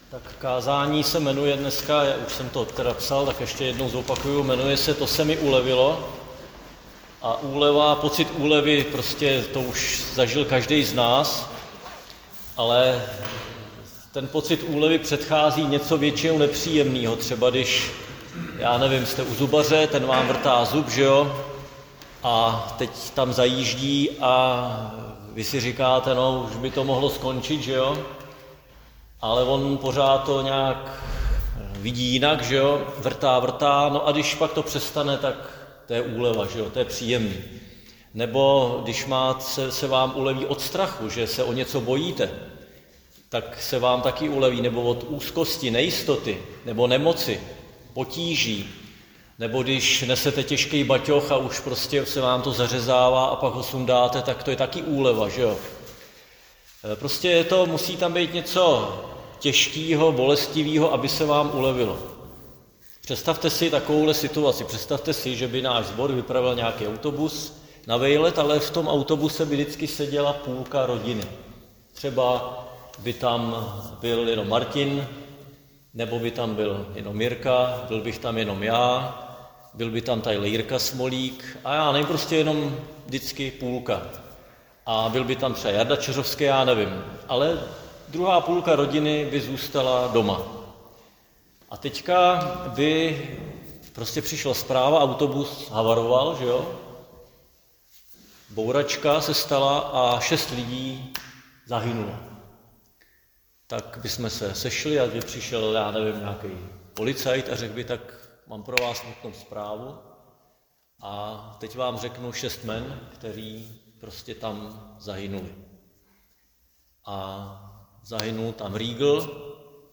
Křesťanské společenství Jičín - Kázání 4.5.2025